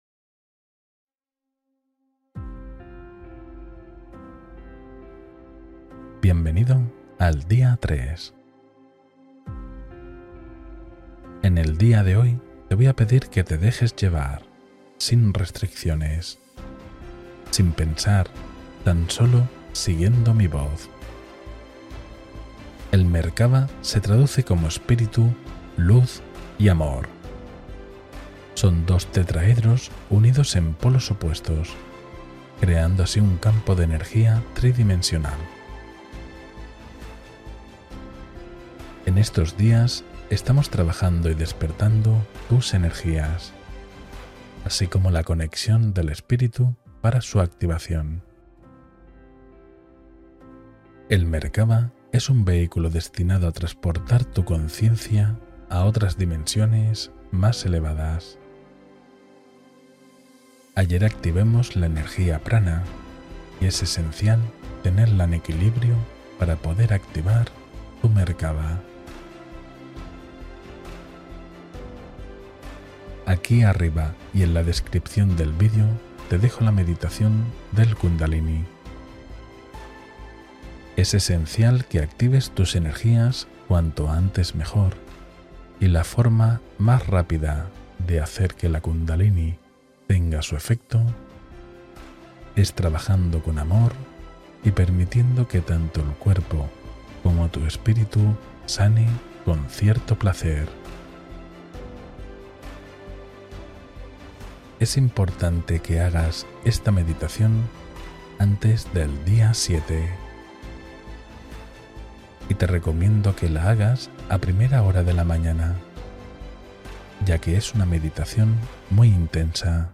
Meditación Merkaba: Viaje Interior hacia la Luz Personal